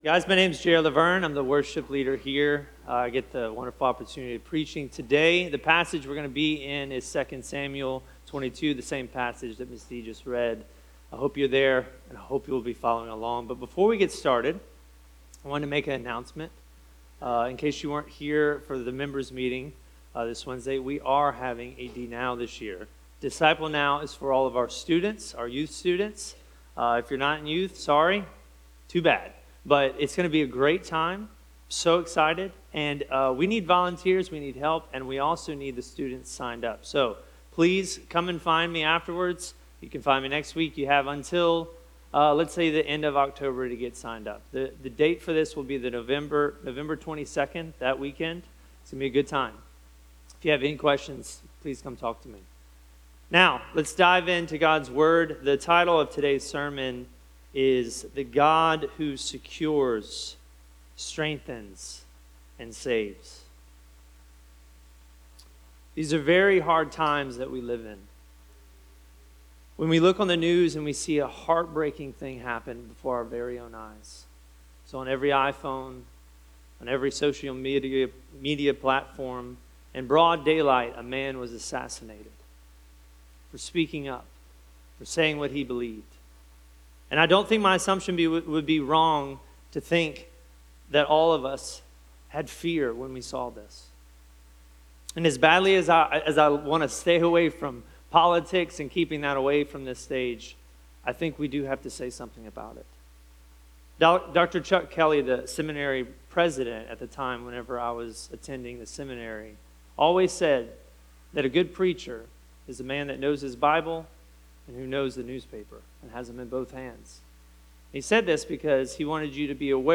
A message from the series "The Book of 2 Samuel."